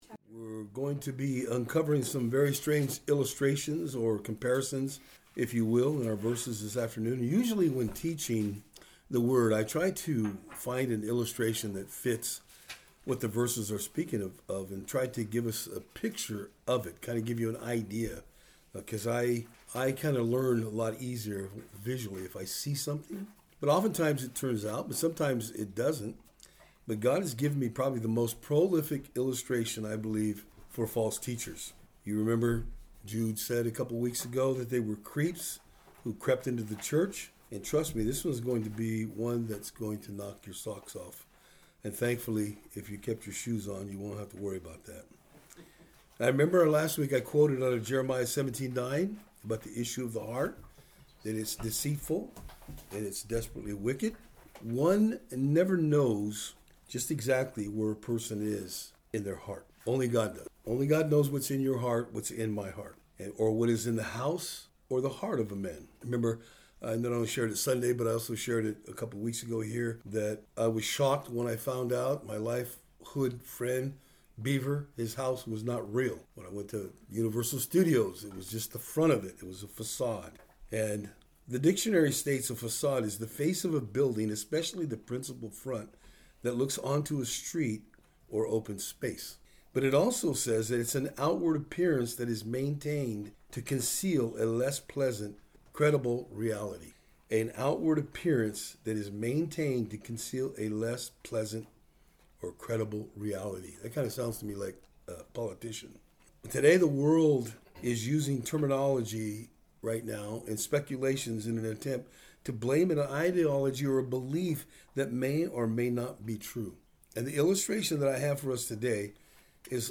Jude 11-13 Service Type: Thursday Afternoon In our study today we will look at how Jude used Metaphors to describe the apostates.